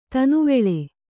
♪ tanuveḷe